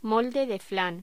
Locución: Molde de flan
voz